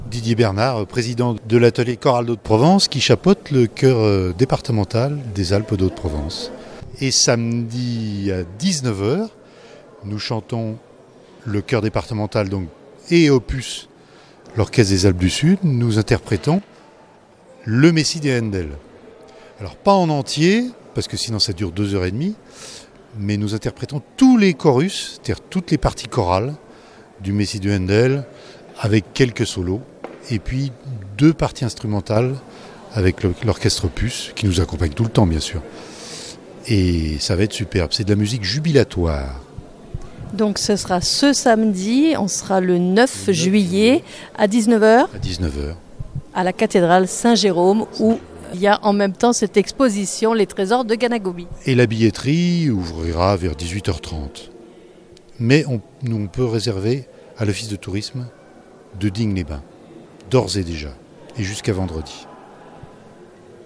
présente au micro